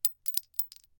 Bullet Shell Sounds
pistol_generic_2.ogg